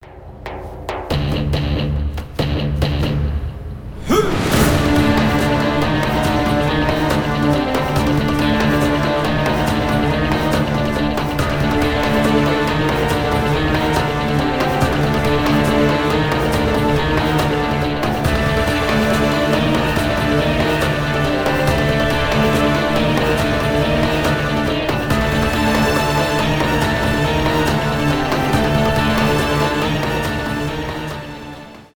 инструментальные